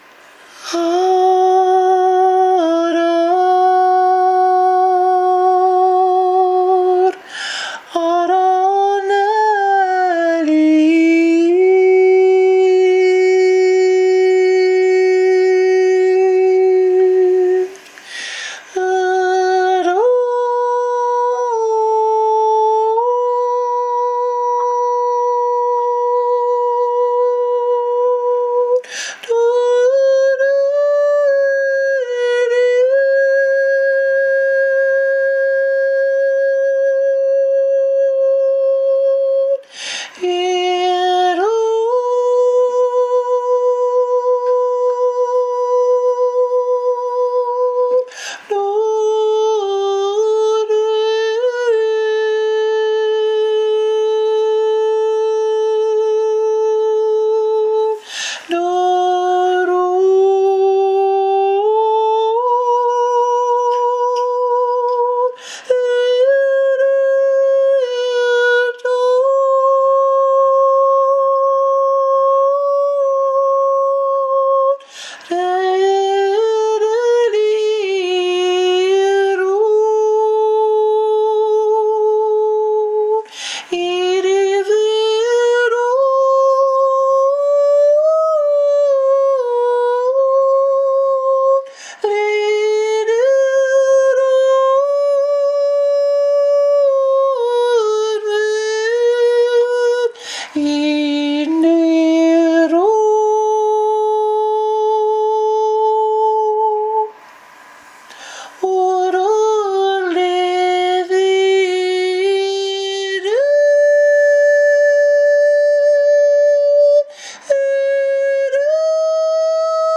Opens with Angel Singing.